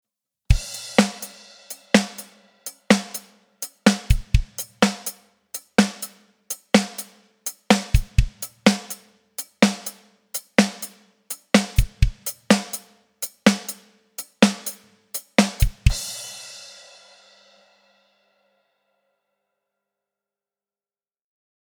Saturated grooves with a Lo-fi vintage soul.
UnityofNoise_MB_Dry_125bpm.mp3